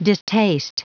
Prononciation du mot distaste en anglais (fichier audio)
Prononciation du mot : distaste